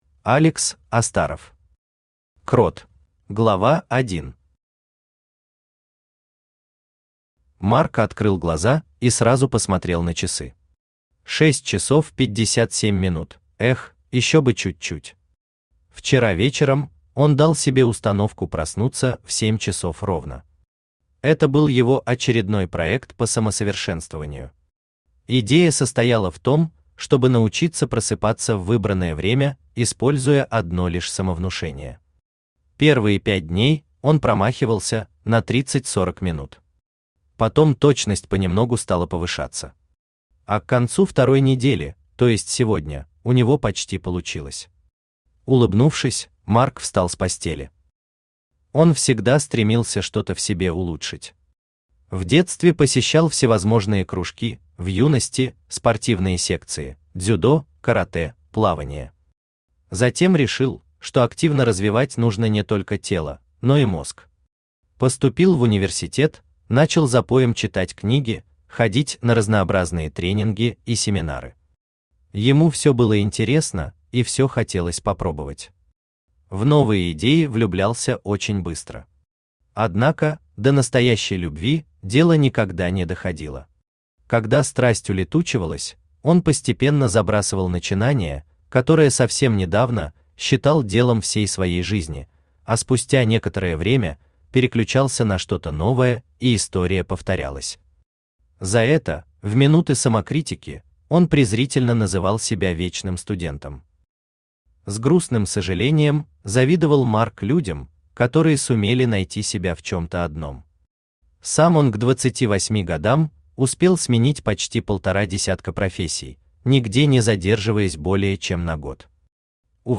Аудиокнига Крот | Библиотека аудиокниг
Aудиокнига Крот Автор Алекс Астаров Читает аудиокнигу Авточтец ЛитРес.